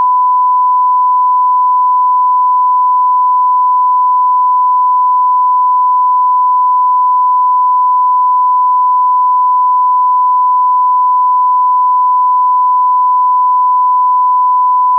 SmartAudio/48000-sine-1khz-mono-s16_le-15s.wav at fd52e99587e8f15c28df951202b45d6693bd498a